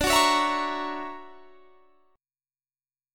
D+M9 chord